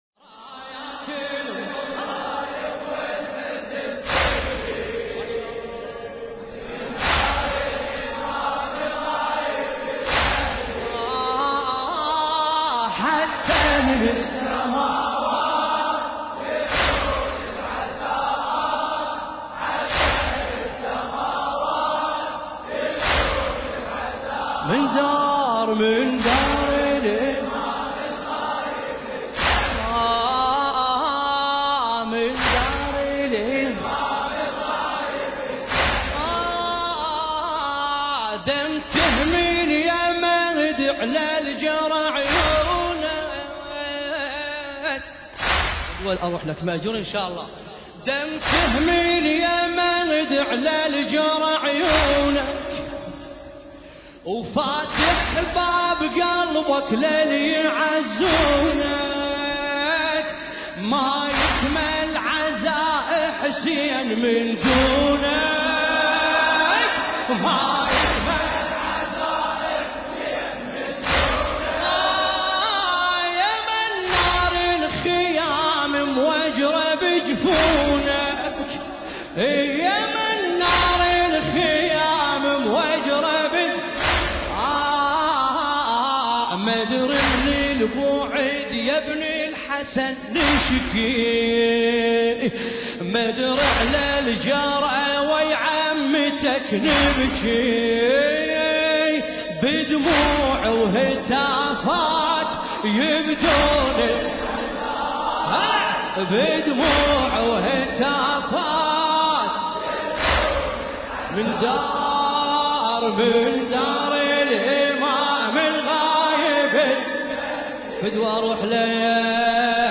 المكان: العتبة العسكرية المقدسة